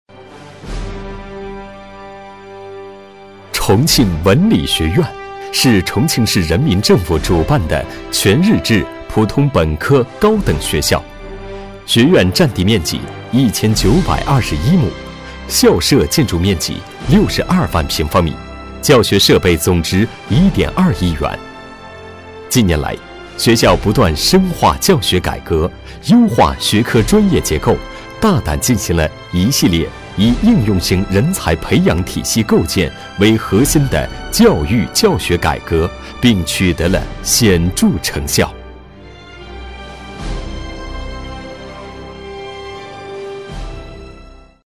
男国167厚重配音-新声库配音网
4 男国167_专题_学校_重庆文理学院_沉稳 男国167
男国167_专题_学校_重庆文理学院_沉稳.mp3